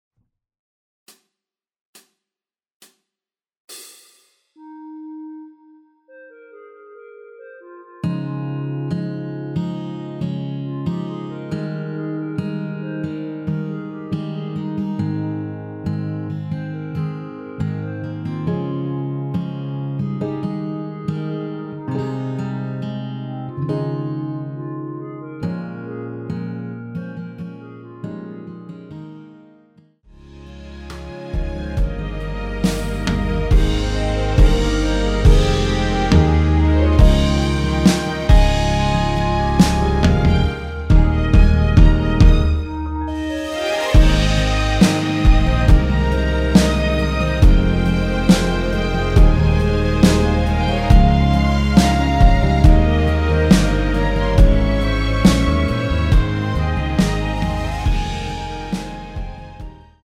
전주 없이 시작하는 곡이라서 시작 카운트 만들어놓았습니다.(미리듣기 확인)
원키 멜로디 포함된 MR입니다.
Ab
앞부분30초, 뒷부분30초씩 편집해서 올려 드리고 있습니다.